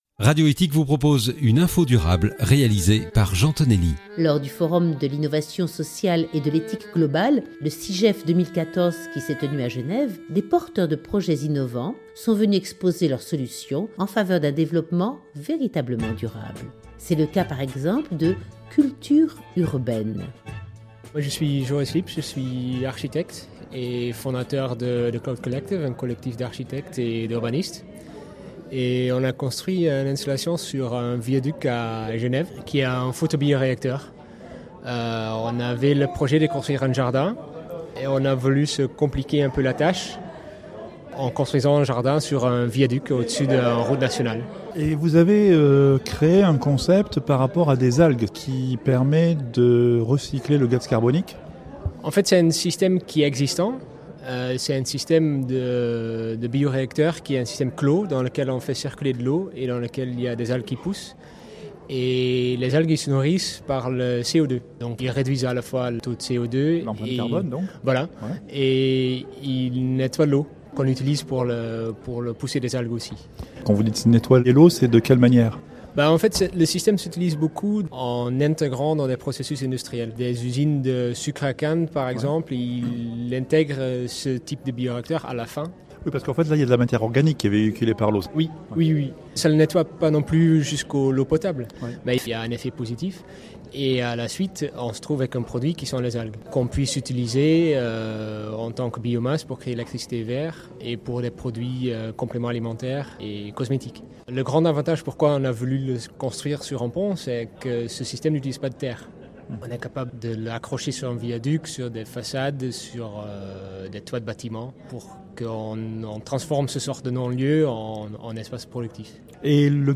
comment fonctionne ce procédé lors du 1er forum de l'innovation sociale et de l'éthique globale à Genève.